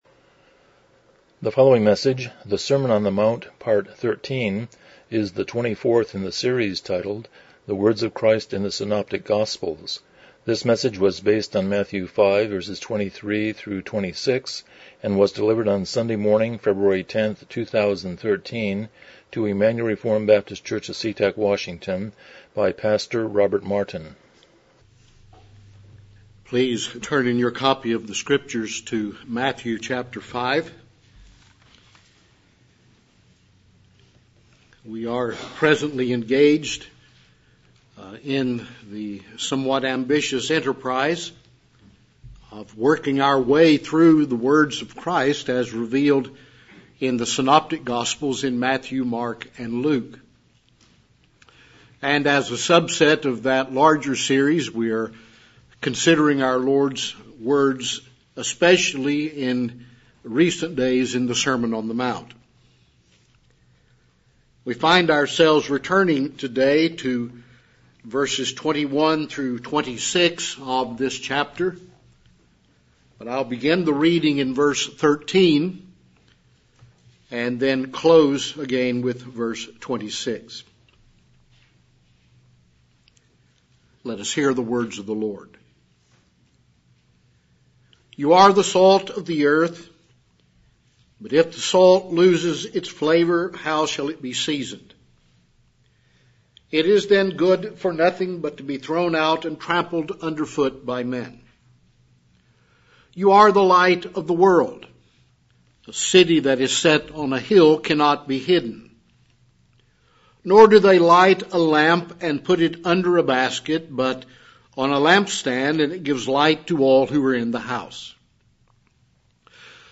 Passage: Matthew 5:23-26 Service Type: Morning Worship